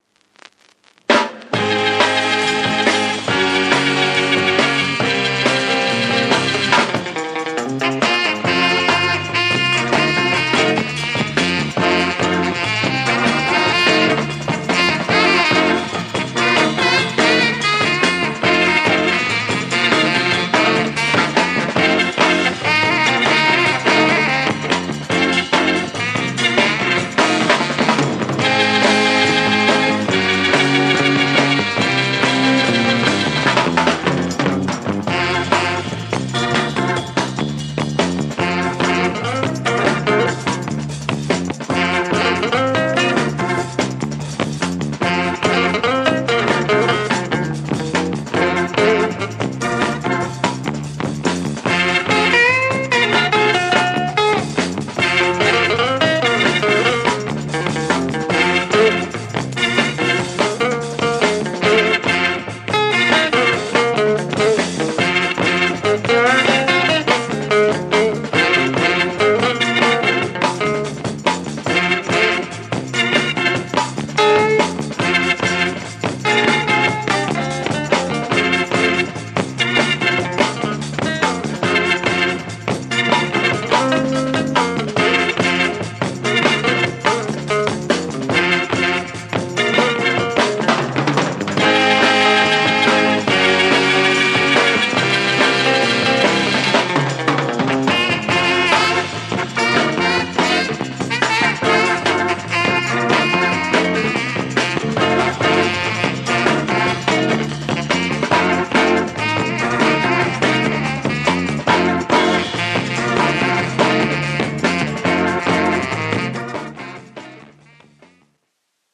Classic Funk soul, out as the great French picture sleeve